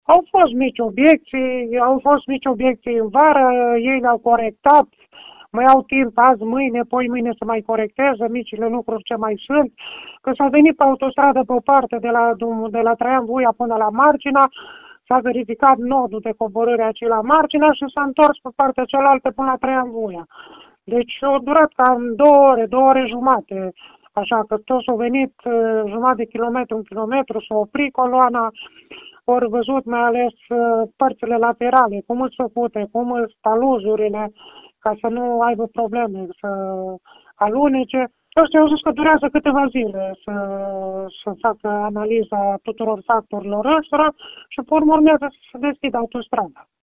La verificări au participat și edilii din zonă, printre care și primarul din Margina, Ionel Costa:
primar-margina-autostrada-.mp3